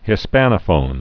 (hĭ-spănə-fōn)